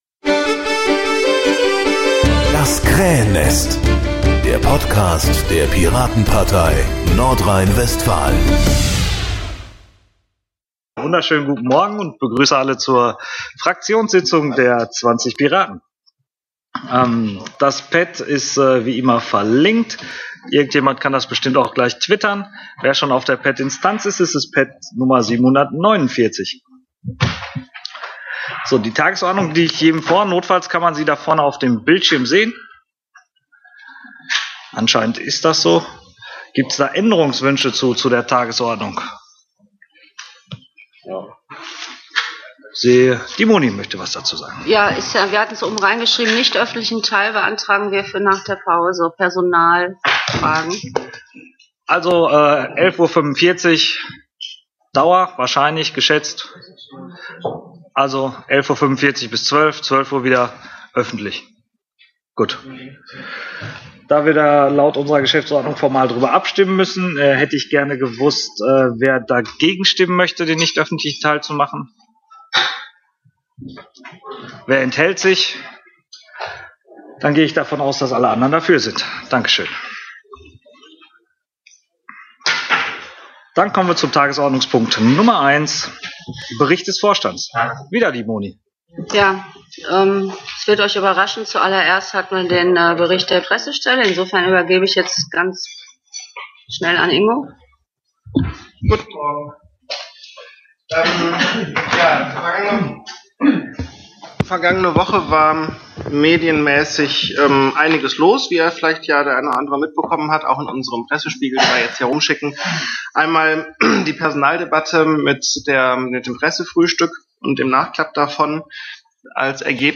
Das Protokoll der Fraktionssitzung in voller Länge Videomitschnitt der Sitzung Audiomitschnitt der Sitzung